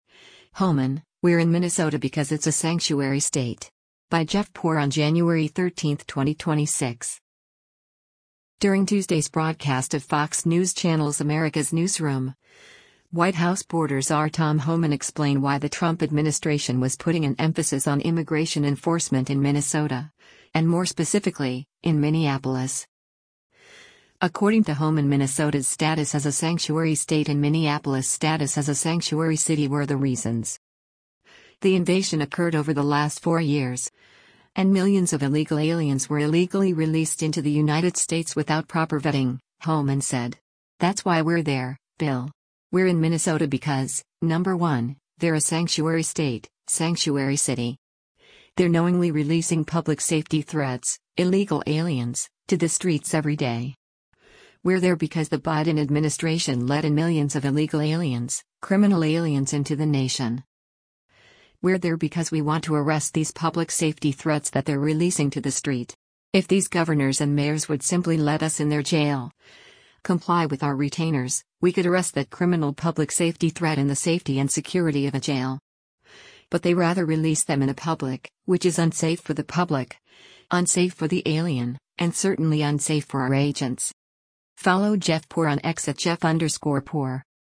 During Tuesday’s broadcast of Fox News Channel’s “America’s Newsroom,” White House border czar Tom Homan explained why the Trump administration was putting an emphasis on immigration enforcement in Minnesota, and more specifically, in Minneapolis.